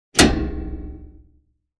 Lawn Mower, Lever Movement 2 Sound Effect Download | Gfx Sounds
Lawn-mower-lever-movement-2.mp3